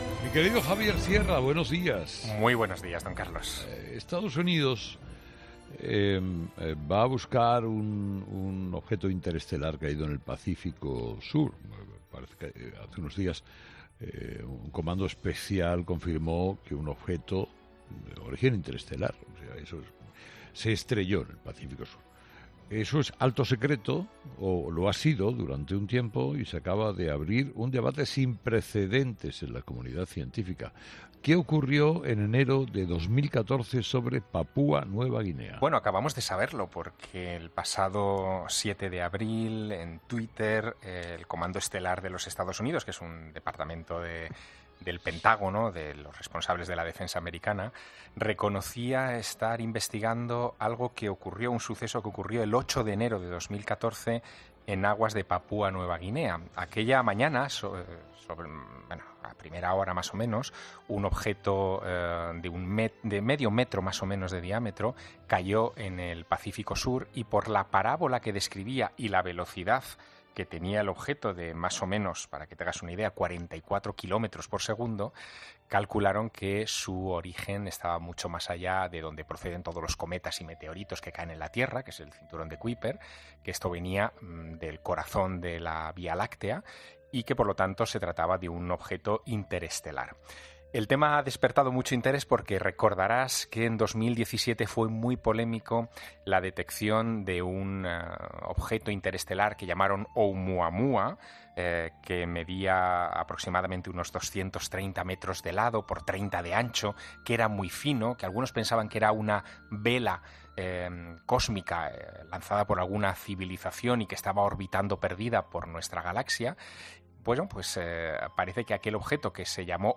Es por ello por lo que en 'Herrera en COPE', Javier Sierra, nos lo cuenta.
"Podría generar vida desde otro sistema planetario. Podría explicar el origen de la vida en nuestro planeta", ha querido enfatizar un entusiasmado Javier Sierra.